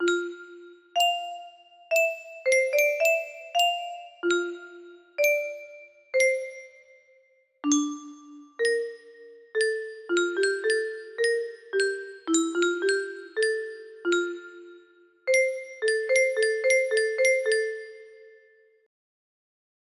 Too short version